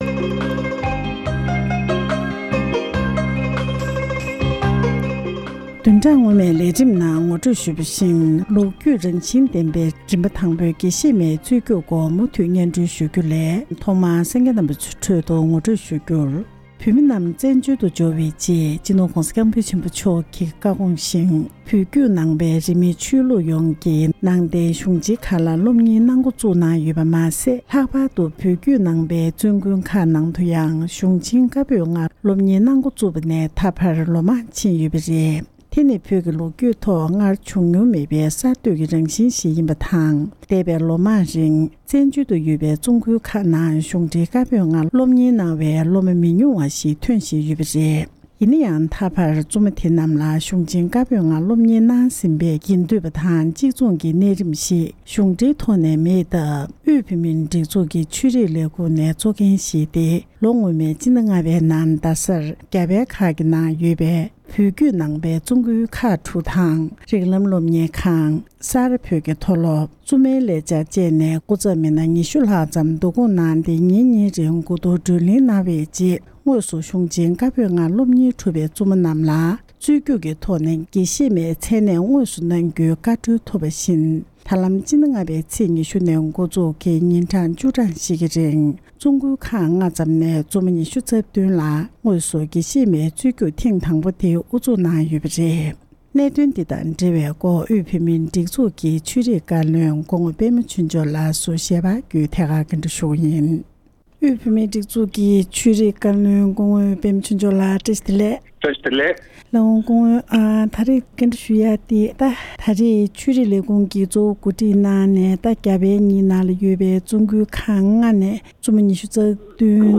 ལོ་རྒྱུས་རང་བཞིན་ལྡན་པའི་རིམ་པ་དང་པོའི་དགེ་བཤེས་མའི་རྒྱུགས་སྤྲོད་དང་འབྲེལ་བའི་སྐོར་འབྲེལ་ཡོད་མི་སྣར་གནས་འདྲི་ཞུས་པ།